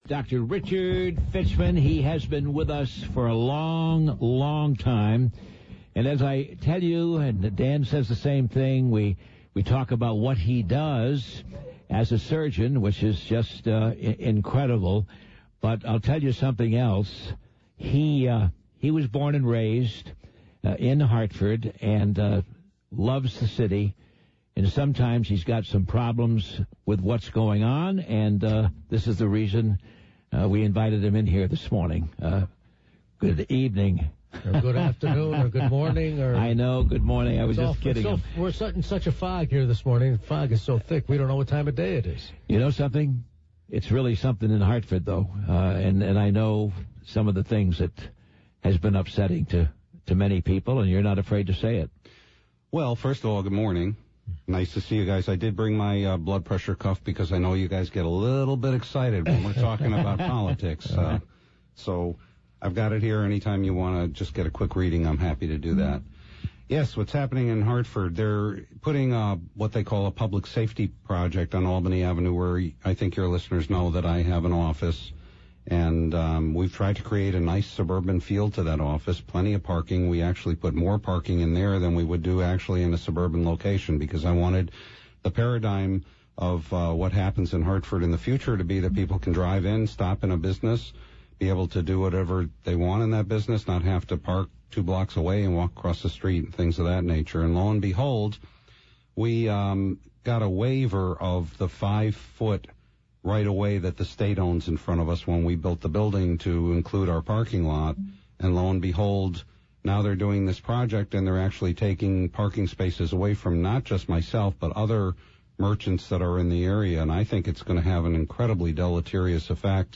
He explains, during this interview.